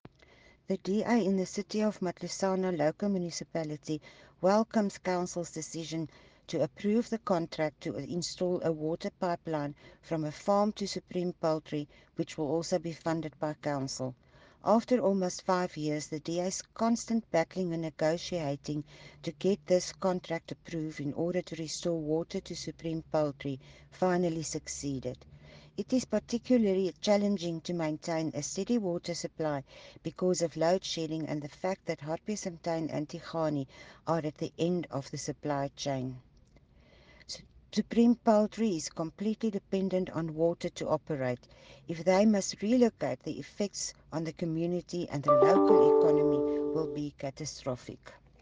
Note to Broadcasters: Please find linked soundbites in